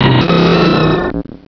pokeemerald / sound / direct_sound_samples / cries / blaziken.aif